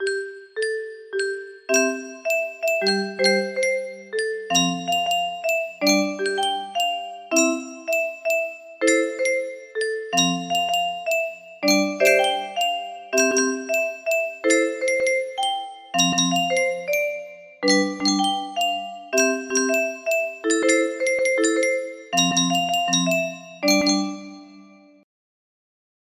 Grand Illusions 30 (F scale)